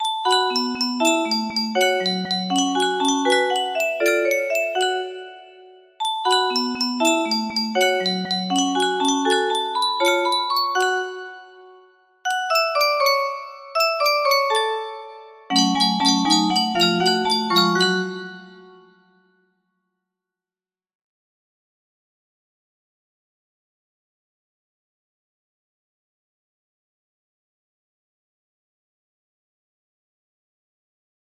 Rain in the Forest music box melody
Full range 60